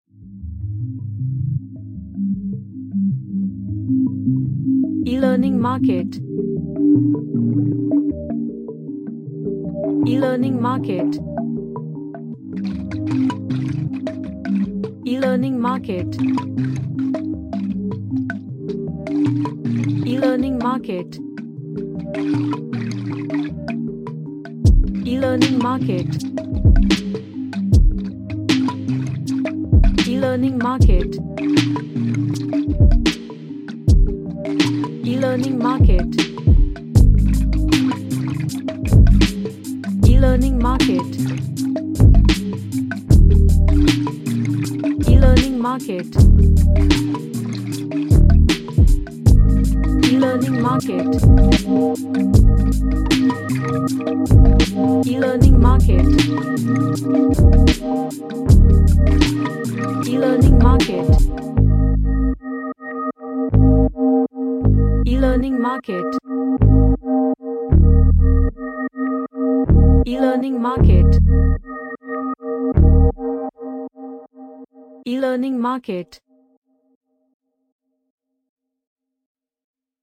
A mystical lofi track with lot of ambience
Happy / CheerfulMagical / Mystical